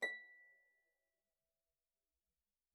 KSHarp_B6_mf.wav